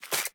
Sfx_creature_babypenguin_hop_05.ogg